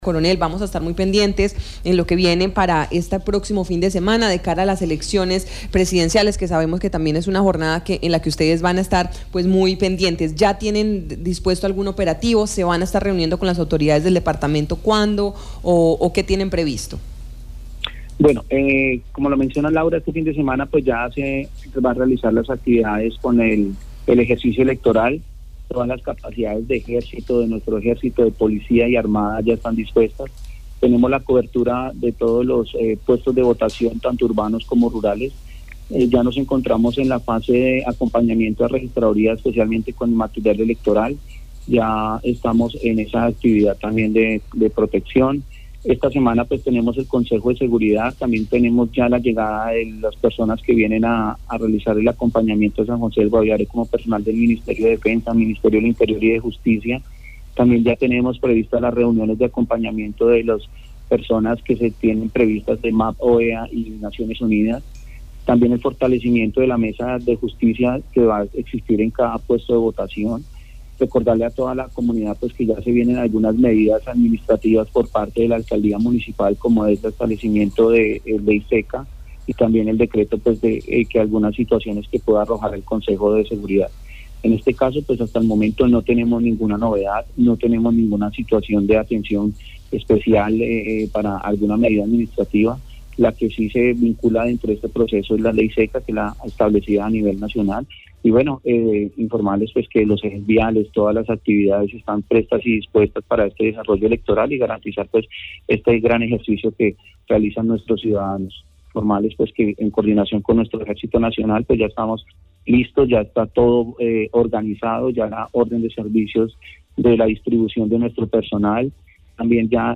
Escuche a coronel Ángel Alexander Galvis Ballén, comandante Departamento de Policía Guaviare.